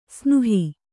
♪ snuhi